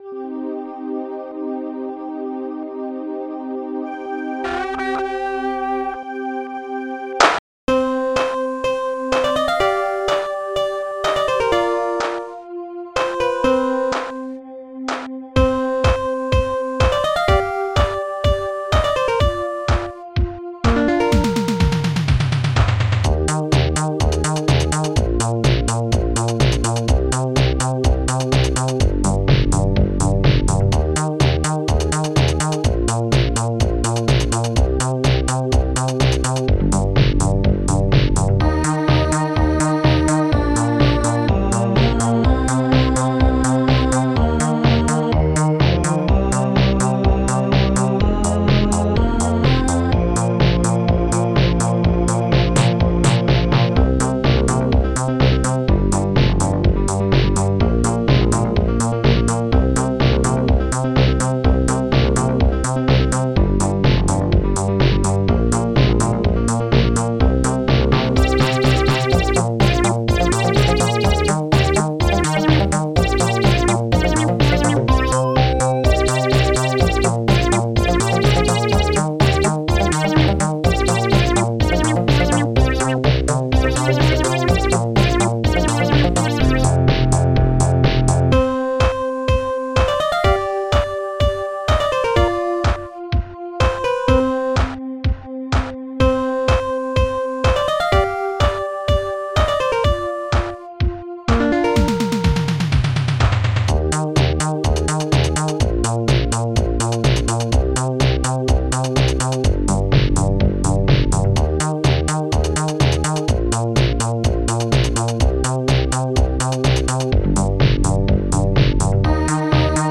Protracker and family
ST-01:bassdrum4
ST-02:snare6
ST-05:strings3long
ST-05:heavyguitar2
ST-01:panflute